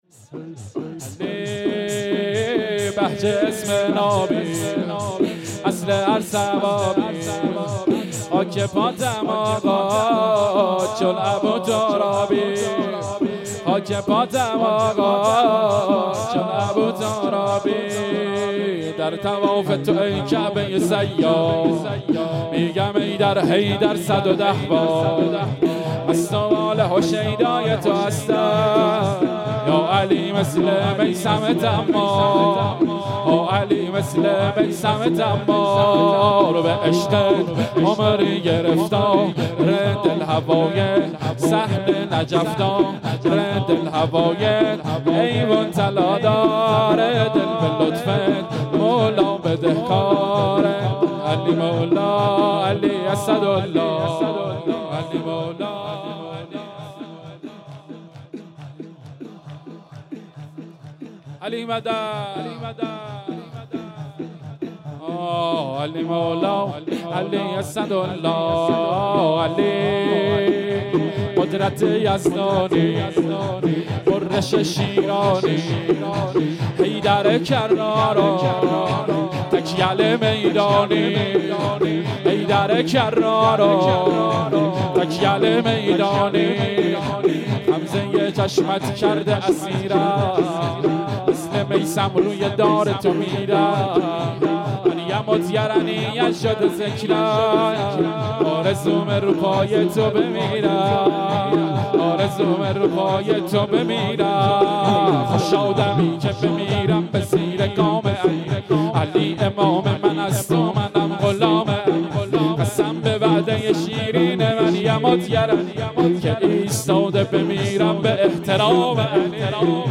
شور 1
شب 23 رمضان
شب های قدر